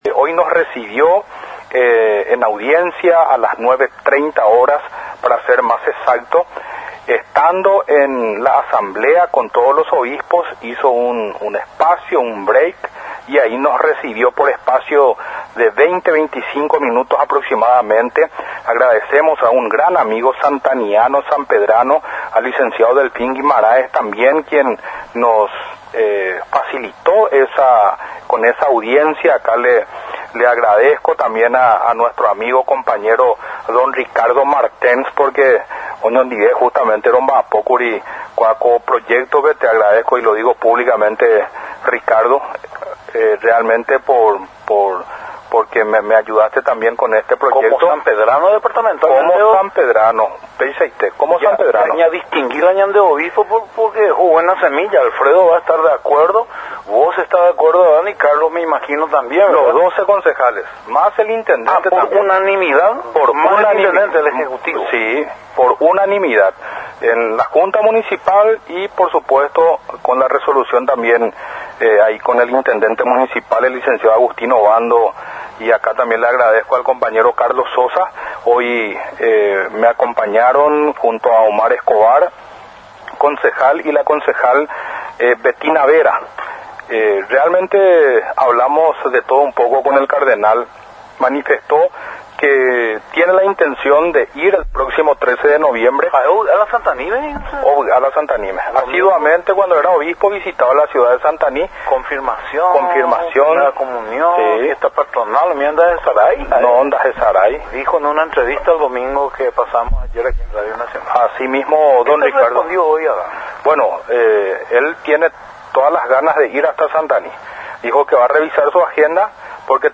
El concejal municipal de Santaní, Abg. Adán Rojas, comentó sobre la visita realizada con sus colegas concejales en la capital del país, donde fueron recibidos por el Cardenal Paraguayo Adalberto Martínez, que recientemente por resolución municipal, fue declarado “Persona Ilustre”.
EDITADO-5-ABG.-ADAN-ROJAS-CONCEJAL.mp3